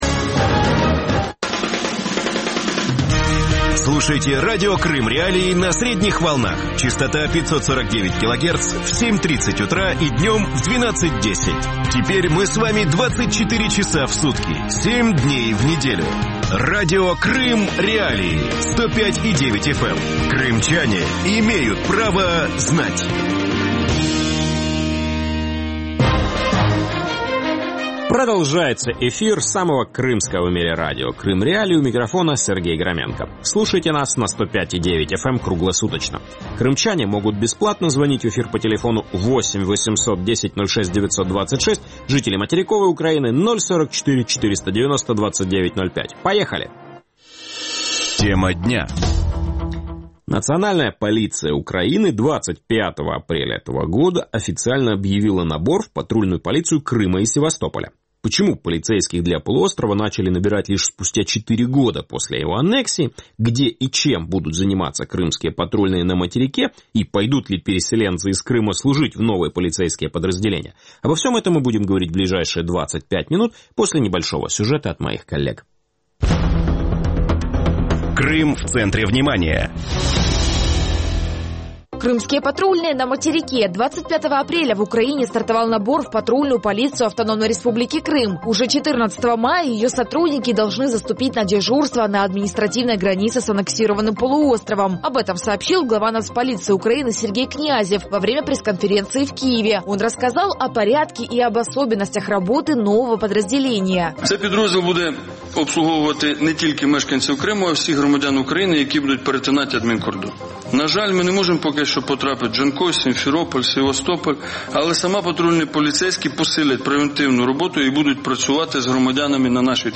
Антон Геращенко народный депутат Украины, член коллегии Министерства внутренних дел Украины.